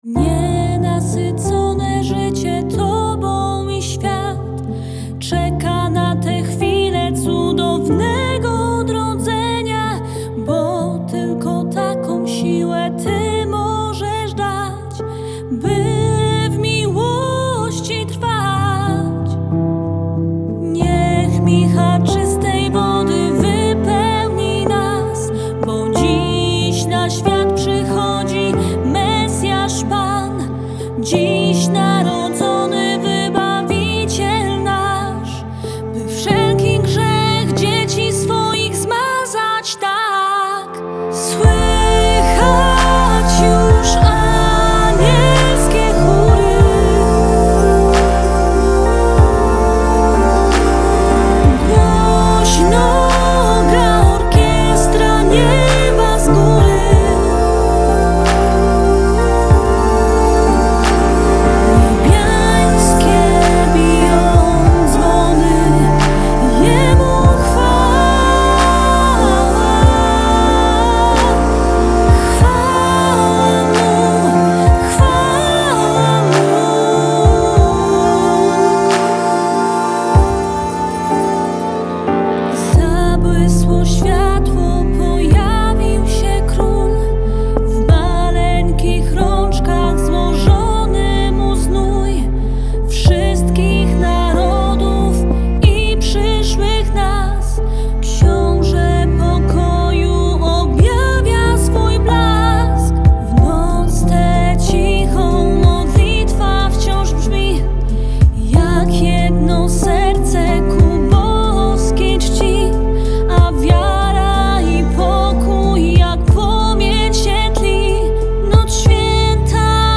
Singiel (Radio)